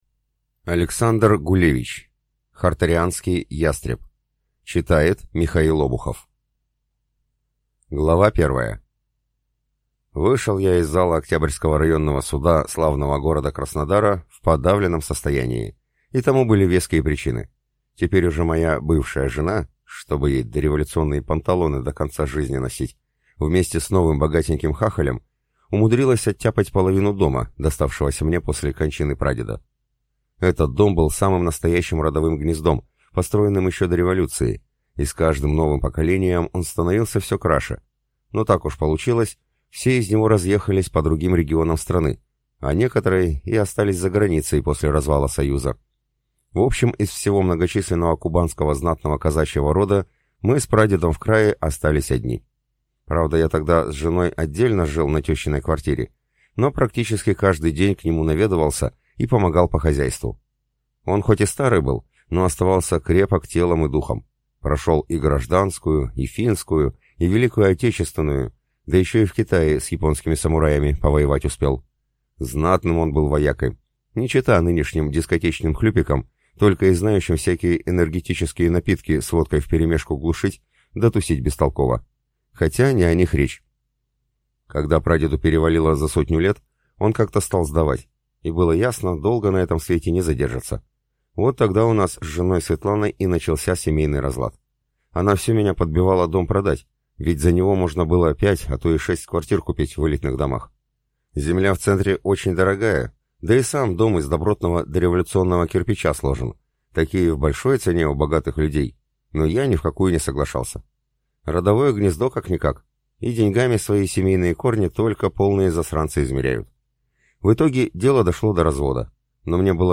Аудиокнига Хортарианский ястреб | Библиотека аудиокниг